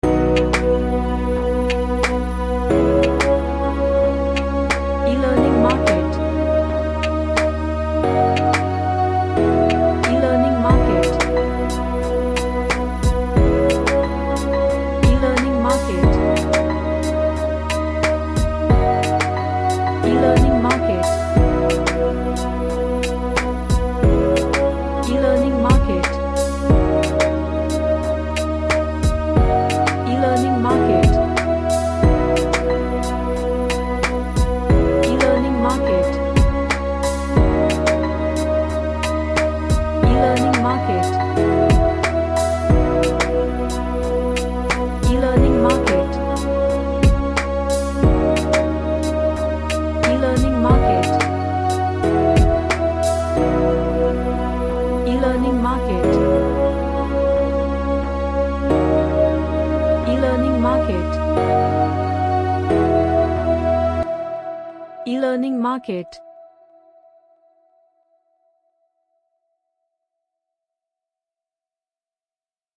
A relaxing pads and piano structured track.
Relaxation / Meditation